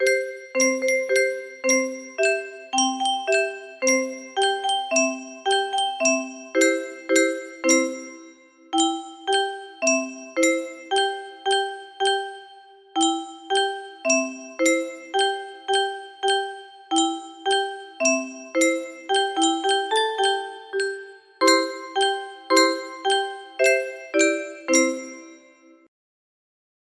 Three Bears music box melody